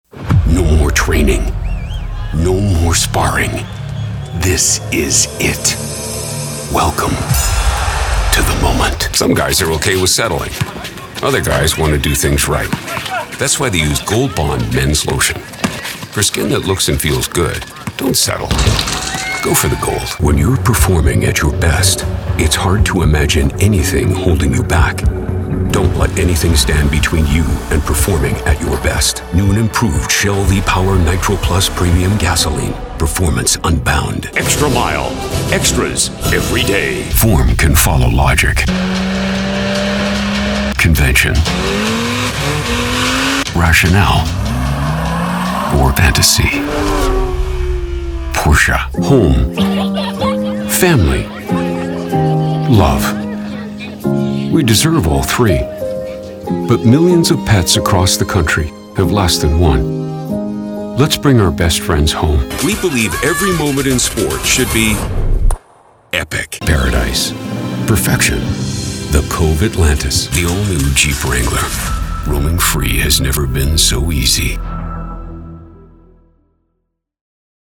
With a voice that has been described as ranging from friendly storyteller to trustworthy and authoritative to gruff and edgy, I can help bring your project to life just the way you imagine it.